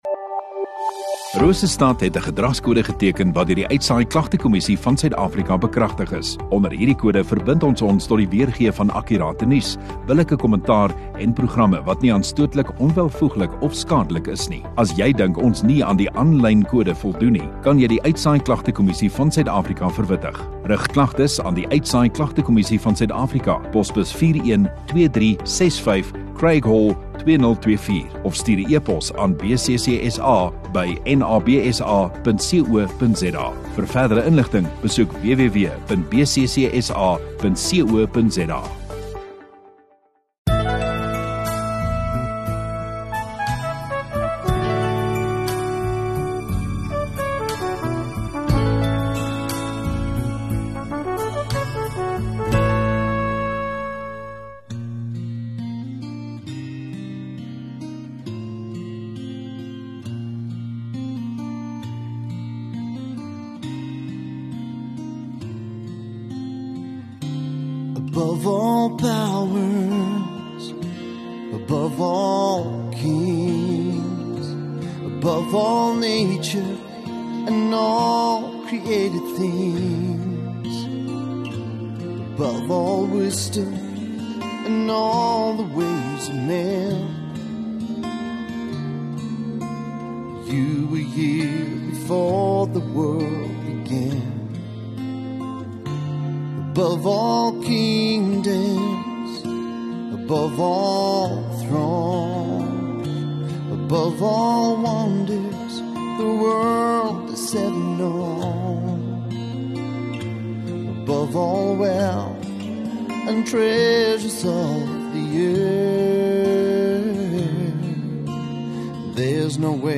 15 Jun Saterdag Oggenddiens